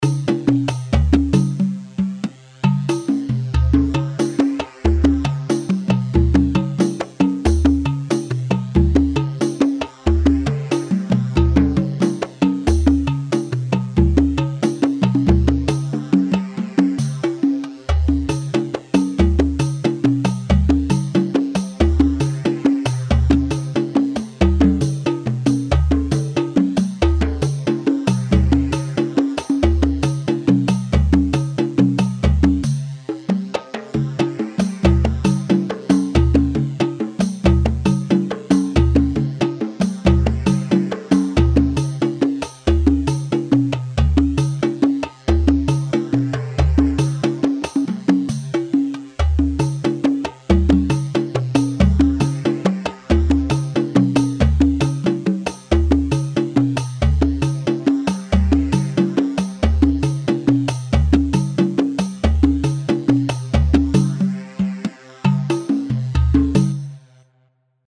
Yemeni
Sharh Lehji 3/4 138 شرح لحجي
Shareh-Lahjee-Yamani-3-4-138.mp3